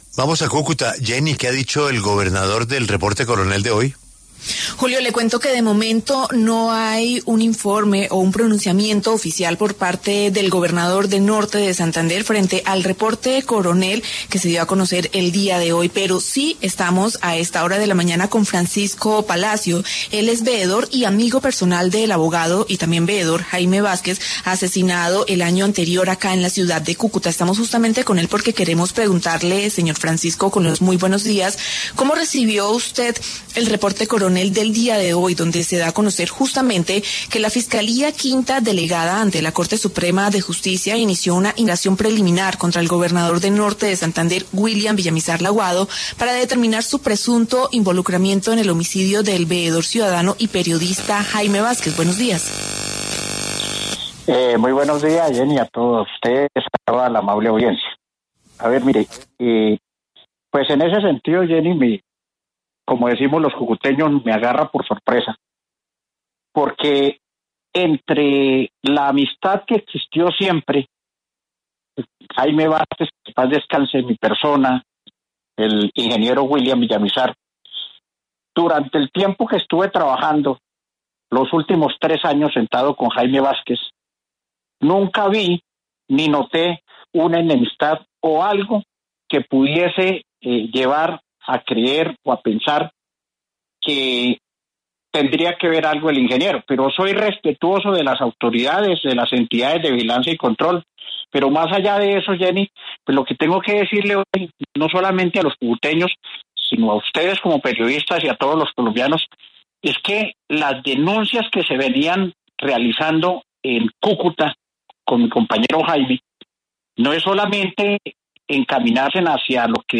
aseguró en los micrófonos de W Radio